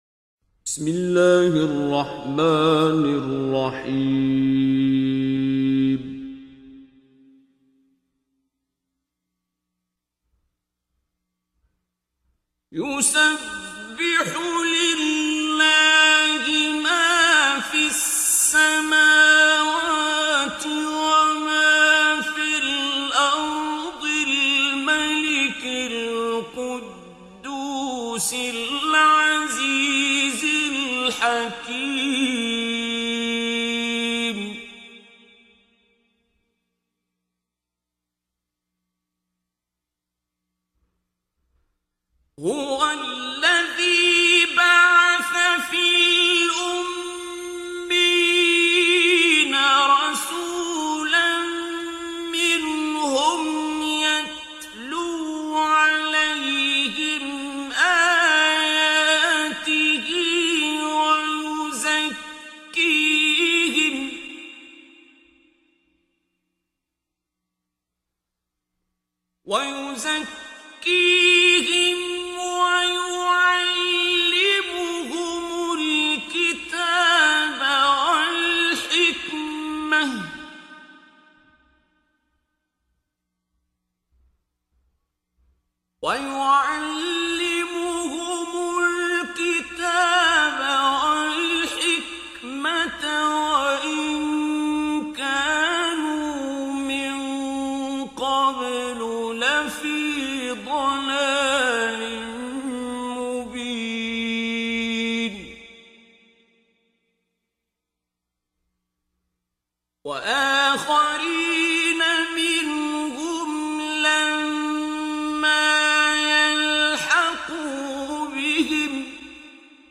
تلاوت سوره جمعه | آیات 1 الی 11 - شیخ عبدالباسط عبدالصمد (فیلم، صوت، متن)
دانلود تلاوت زیبای سوره جمعه آیات 1 الی 11 با صدای دلنشین شیخ عبدالباسط عبدالصمد
در این بخش از ضیاءالصالحین، تلاوت زیبای آیات 1 الی 11 سوره مبارکه جمعه را با صدای دلنشین استاد شیخ عبدالباسط عبدالصمد به مدت 8 دقیقه با علاقه مندان به اشتراک می گذاریم.